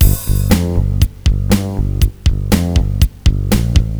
drumnbass120_loop.wav